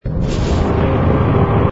engine_br_fighter_start.wav